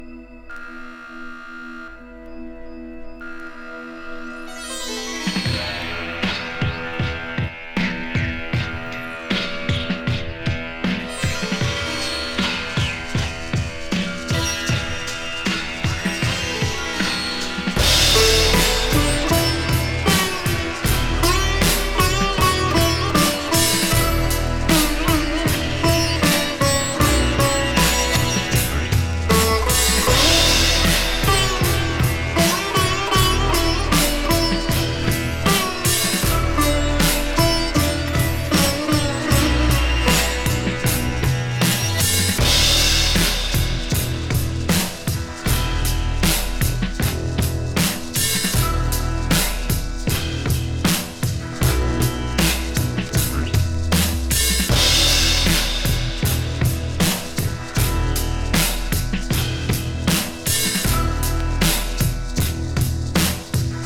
シタール入りのサイケトラック！